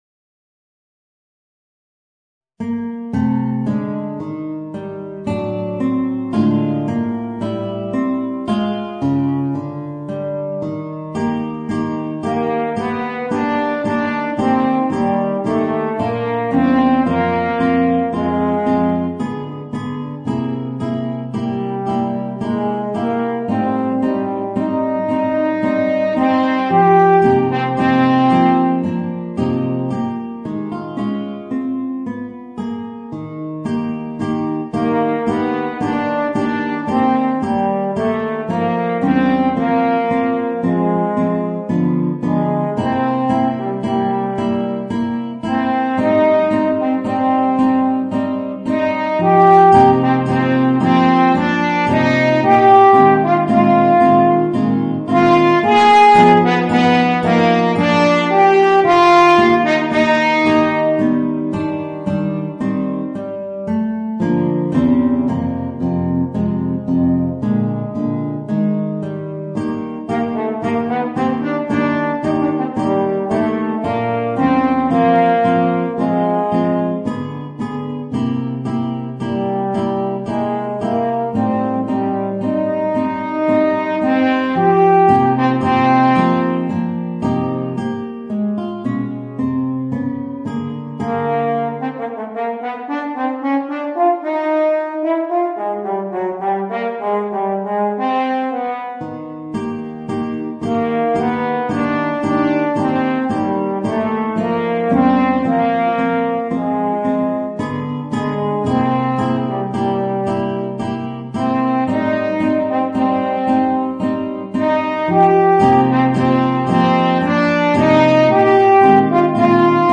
Voicing: Guitar and Horn